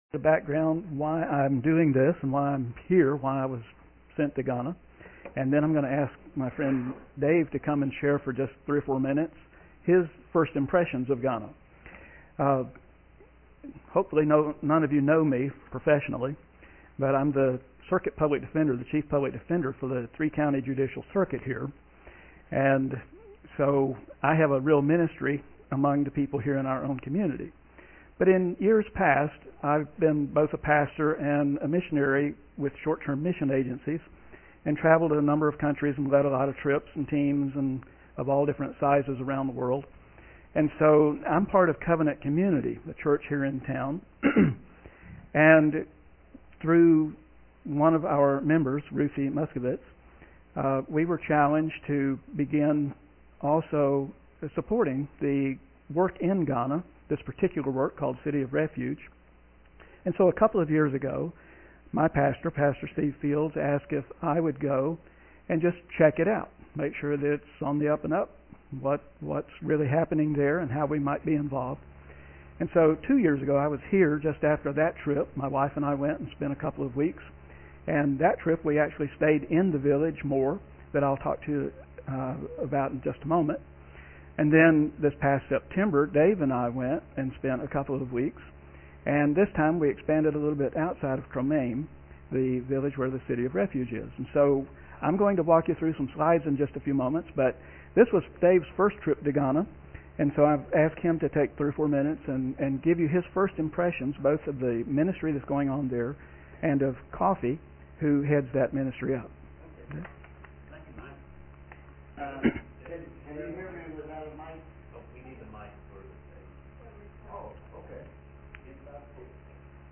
Guest Speakers